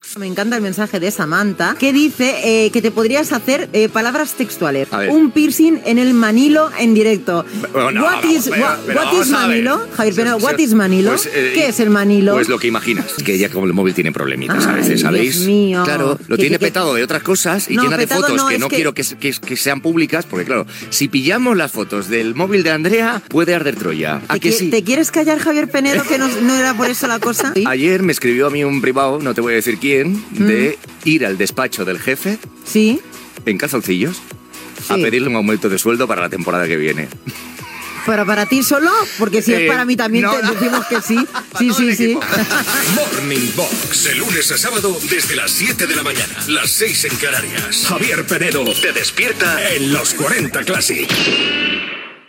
Missatges de l'audiència i indicatiu del programa
FM